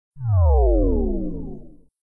power_up_malus.mp3